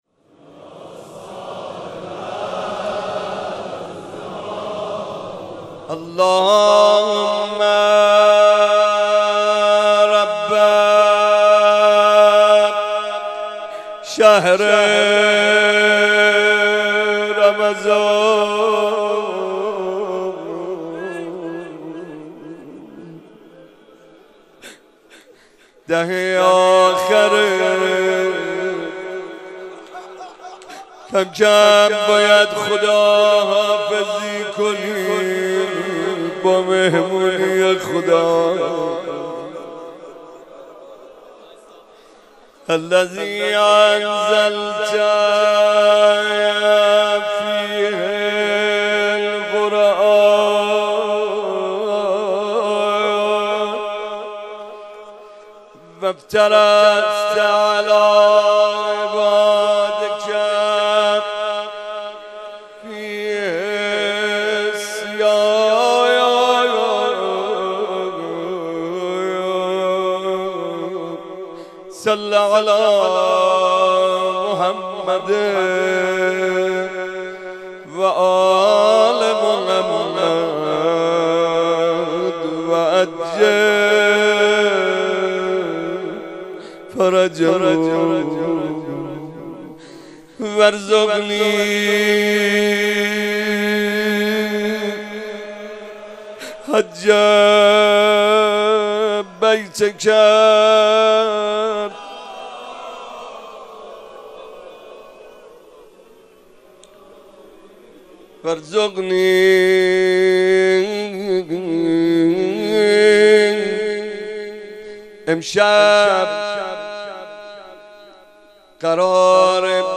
مناجات